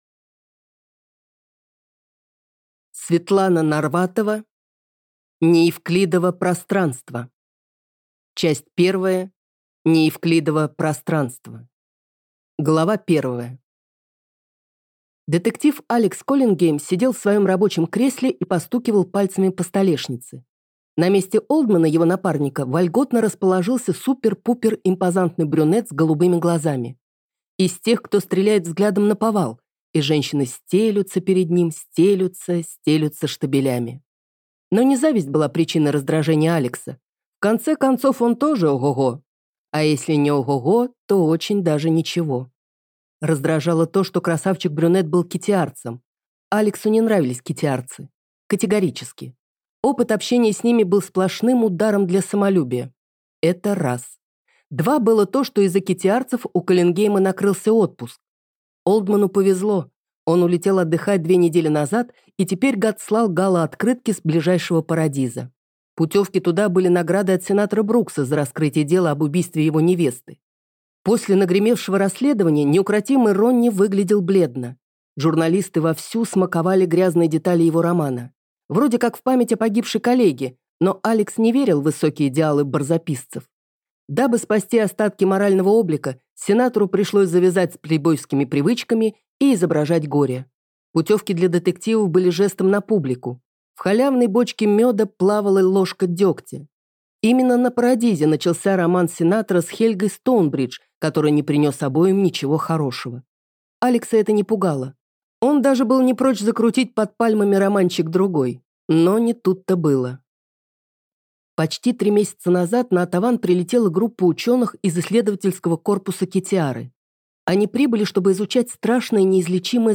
Аудиокнига Неевклидово пространство | Библиотека аудиокниг